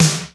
HR16B SNR 10.wav